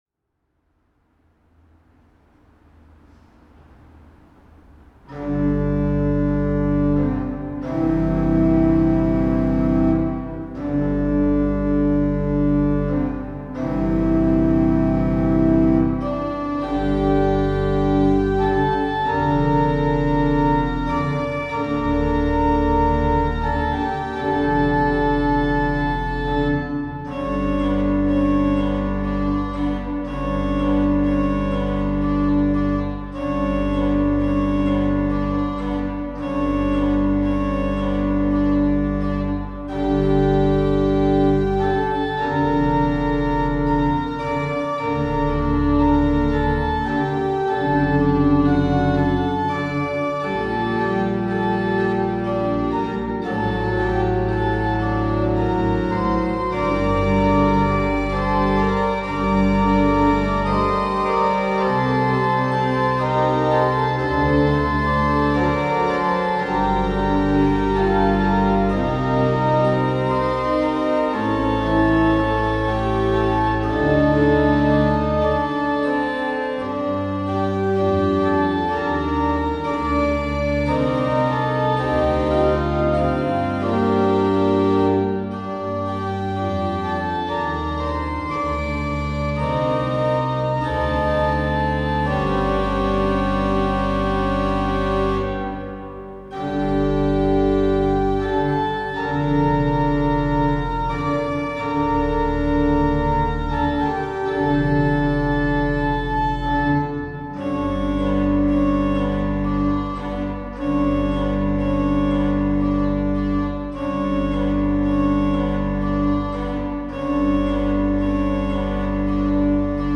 2020 | Weihnachtsgruss von der Kleuker-Orgel der Petrikirche
Ein musikalischer Weihnachtsgruß aus dem Lockdown
Liedbearbeitungen zu Advent und Weihnachten (2020)